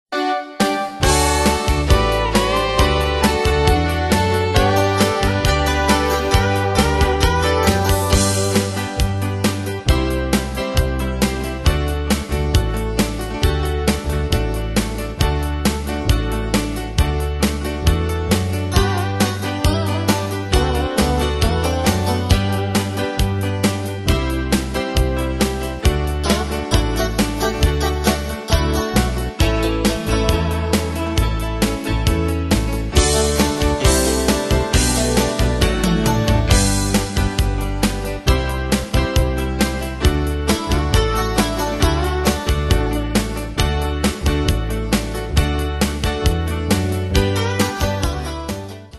Style: Country Année/Year: 1991 Tempo: 135 Durée/Time: 3.16
Pro Backing Tracks